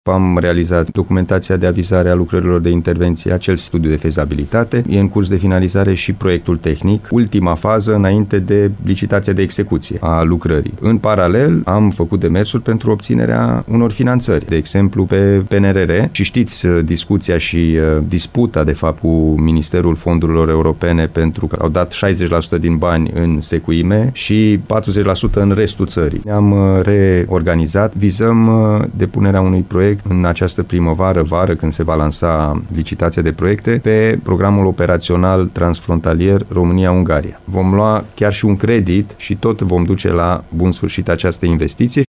Președintele Consiliului Județean Timiș, Alin Nica, susține că nu s-a pierdut nicio o oportunitate de reabilitare a celei mai vechi clădiri, monument istoric al Timișoarei, Castelul Huniade.